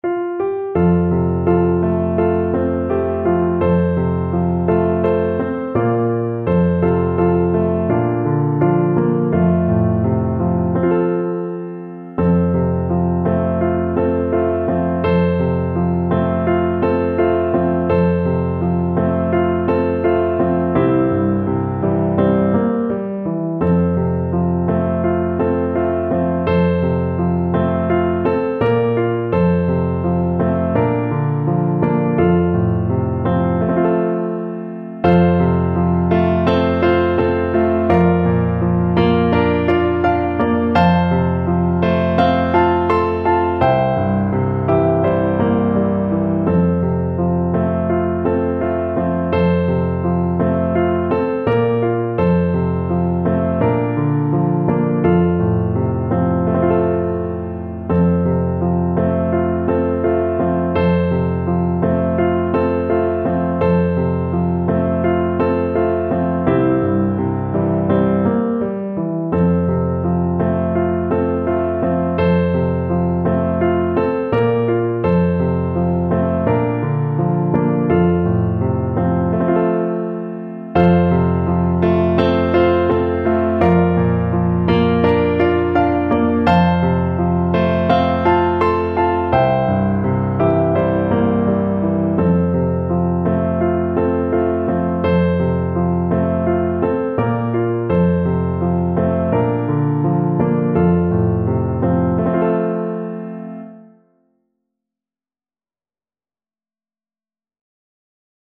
Clarinet version
Andante =c.84
4/4 (View more 4/4 Music)